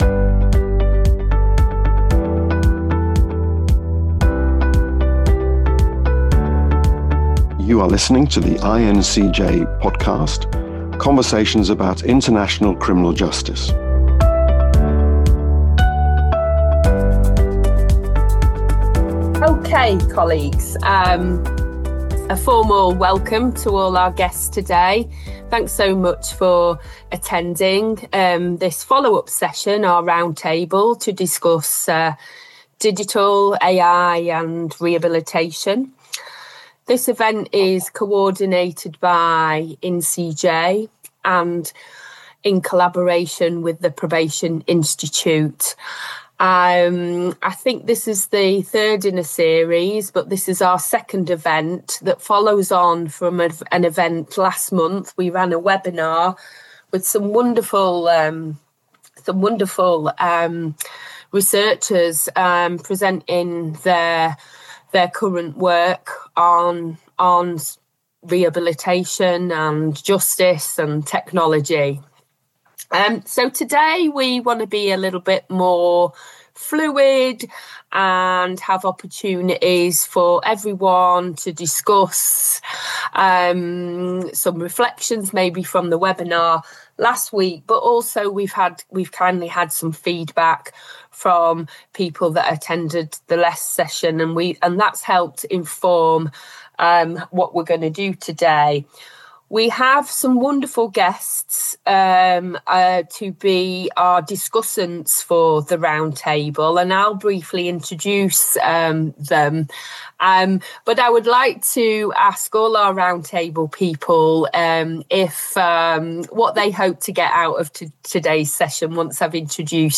The recent IN-CJ and Probation Institute roundtable on AI and rehabilitation brought together an unusually rich mix of voices from practice, research, service development, policy, design, and international justice settings.